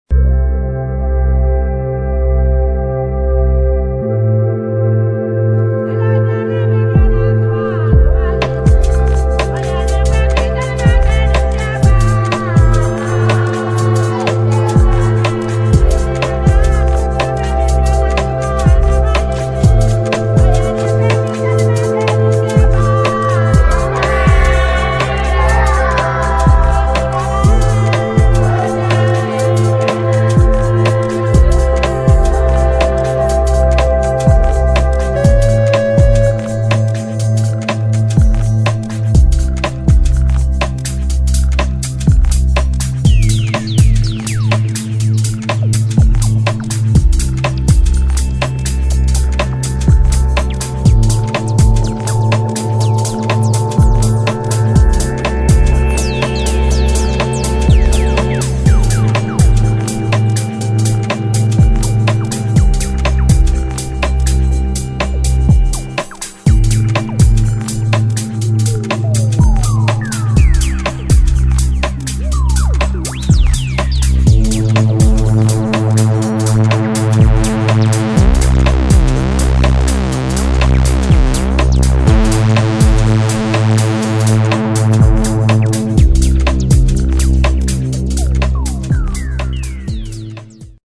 [ DEEP HOUSE / ACID / TECHNO ]